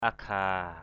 /a-ɡʱa:/